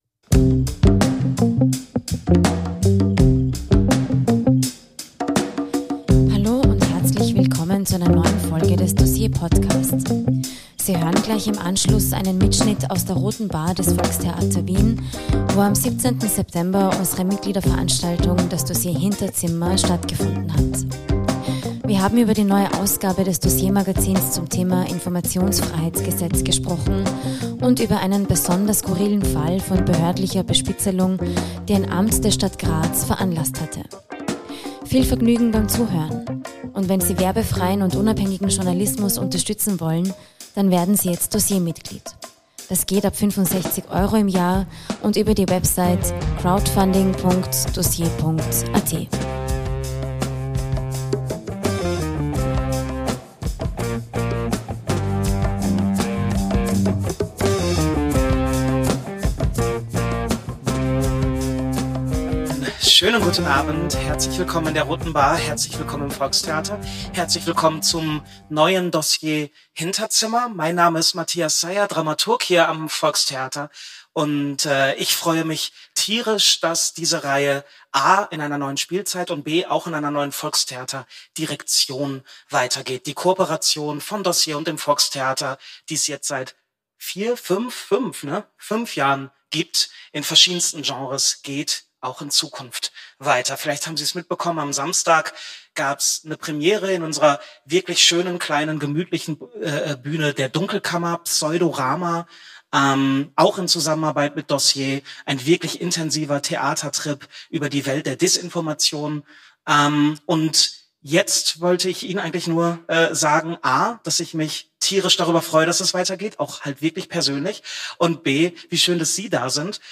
Ein Mitschnitt vom DOSSIER-Hinterzimmer in der Roten Bar des Volkstheater Wien